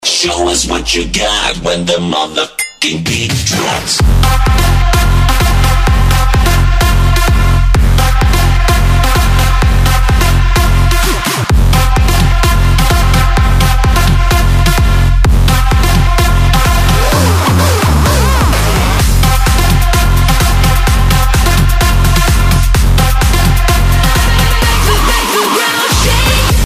Категория: Клубные рингтоны